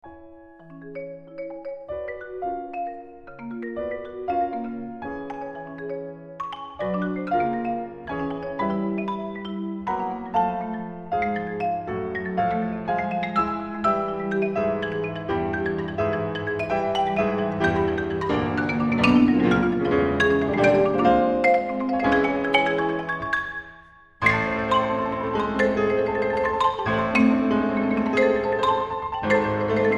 for marimba and piano
Sempre legato ma ritmico 3.27